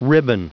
Prononciation du mot ribbon en anglais (fichier audio)
Prononciation du mot : ribbon
ribbon.wav